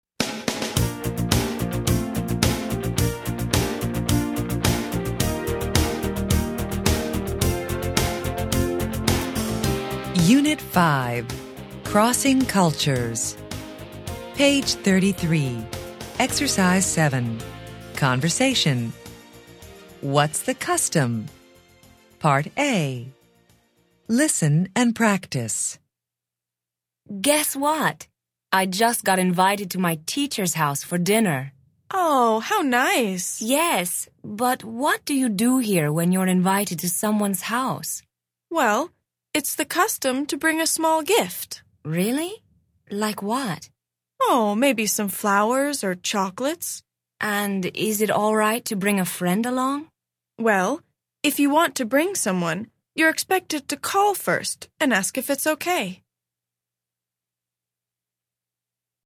interchange3-level3-unit5-ex7-conversation-track9-students-book-student-arcade-self-study-audio.mp3